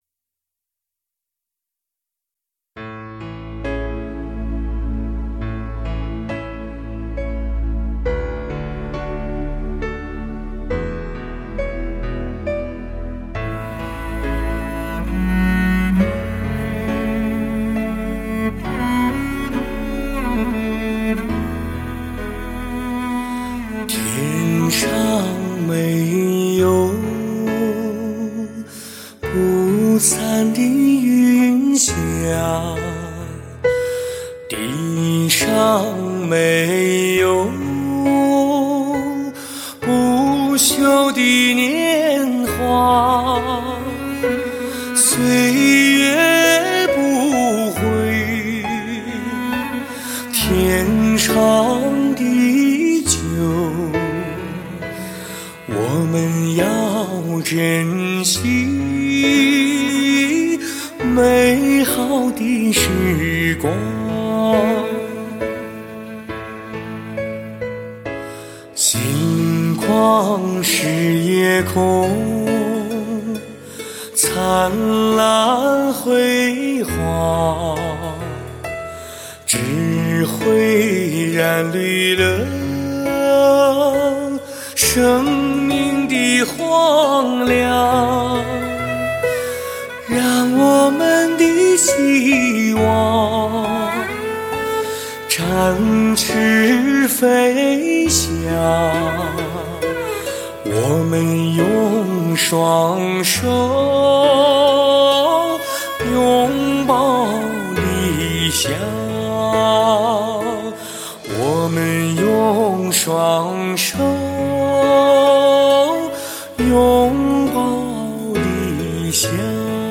24BIT-96KHZ 纯数码录音制作
新生代民族音乐接班人，民歌的中坚力量，带给您无限的听觉盛宴。
低回处，如涓涓细流安详恬静；高亢时，如黄河怒涛奔放激昂。